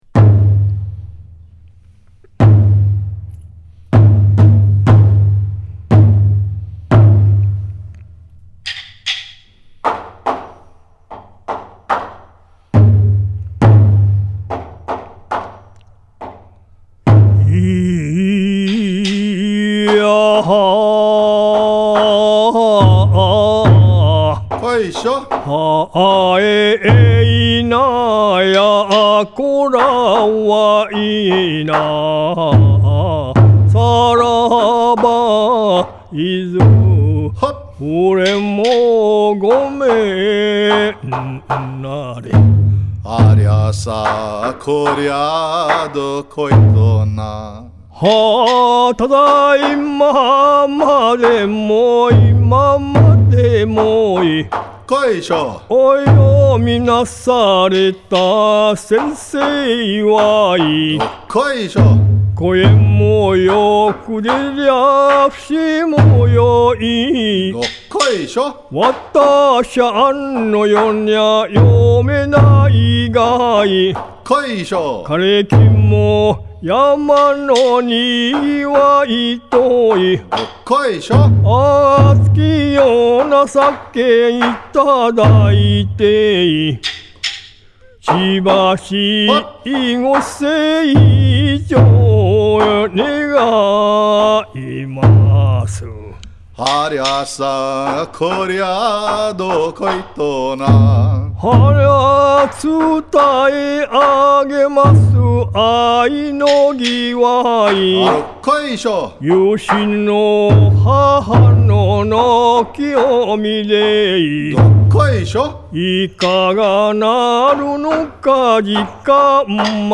•  Iwakuni style
The Iwakuni style of singing was popular at that time.